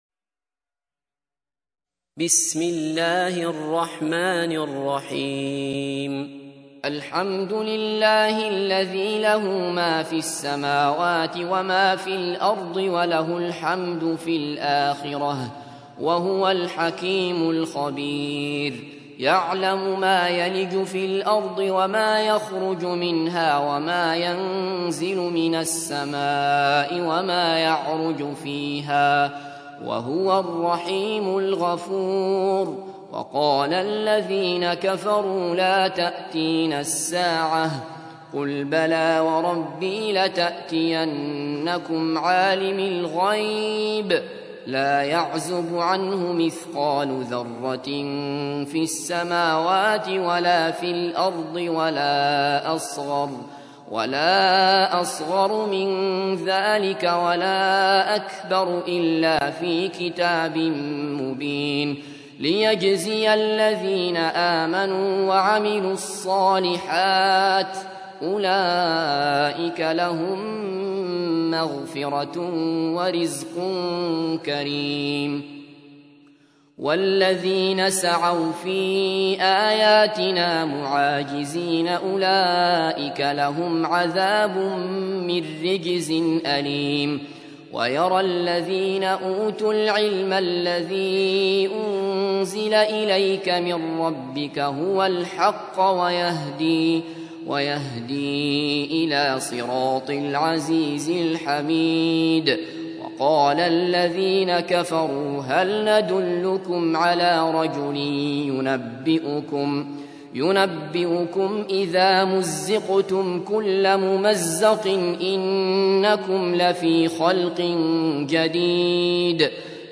تحميل : 34. سورة سبأ / القارئ عبد الله بصفر / القرآن الكريم / موقع يا حسين